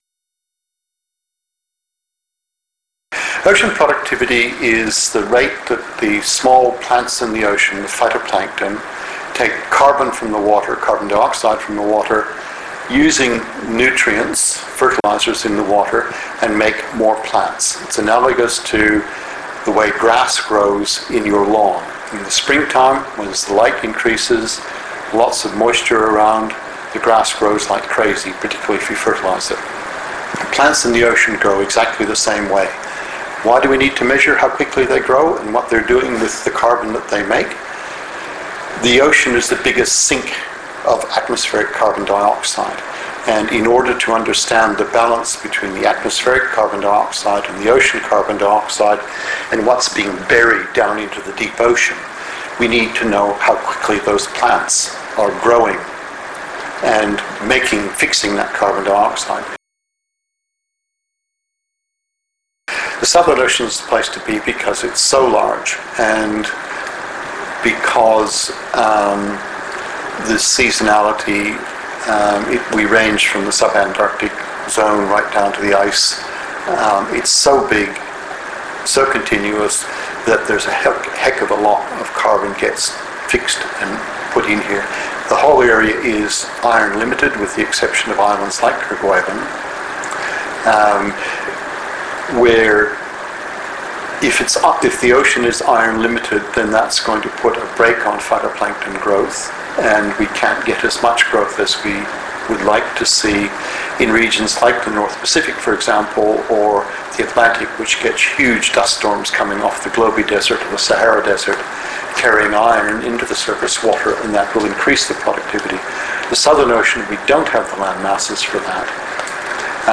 Scientist Interviews